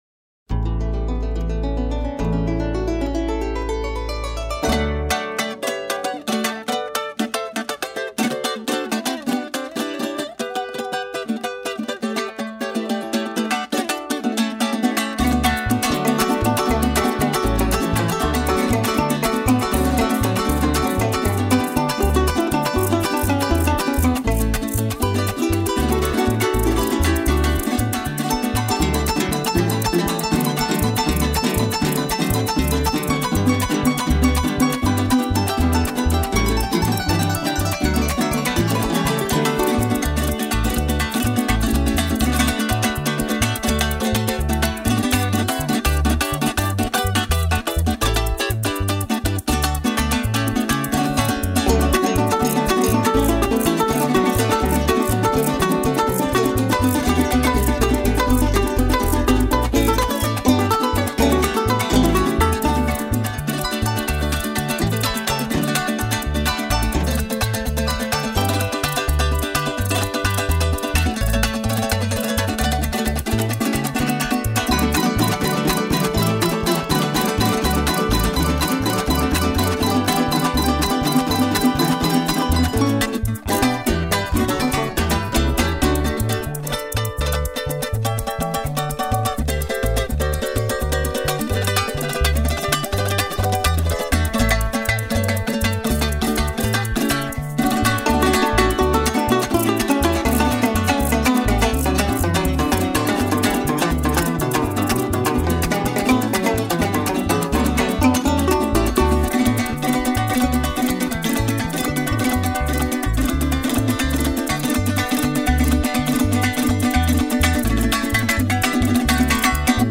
Música latina
La música de América Latina